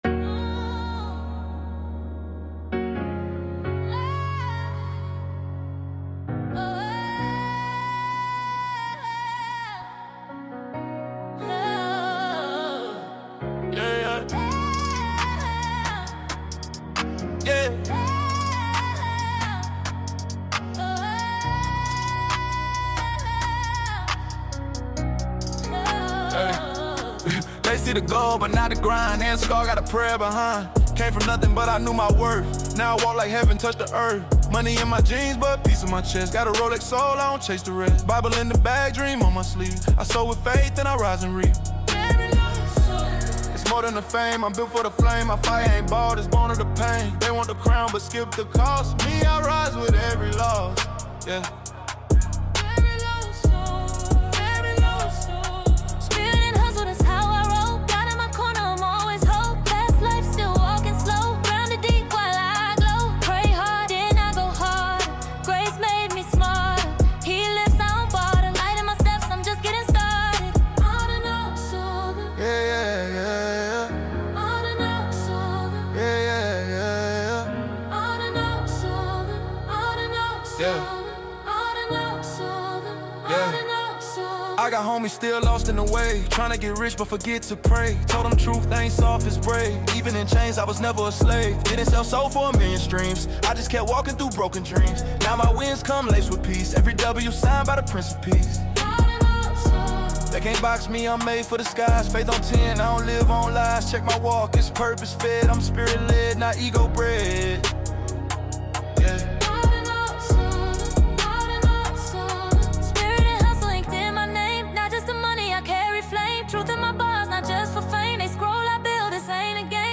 Let this anthem lift your faith and fire up your purpose.